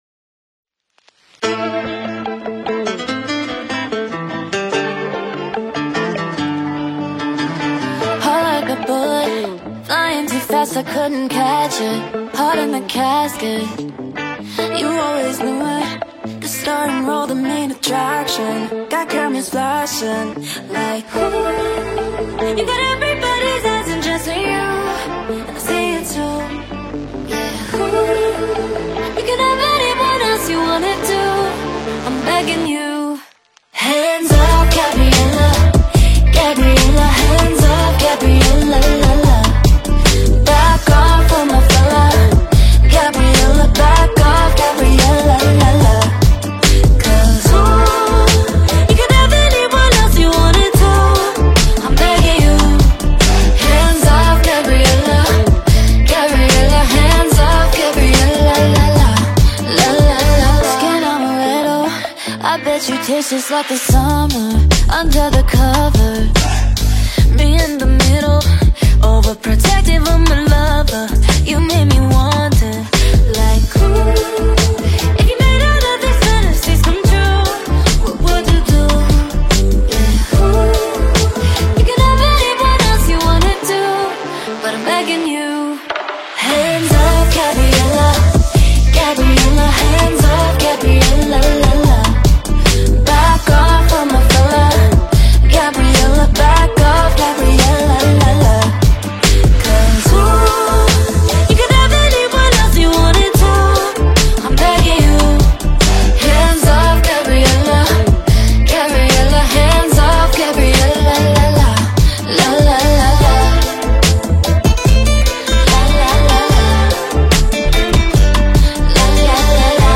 with Lead Vocals mp3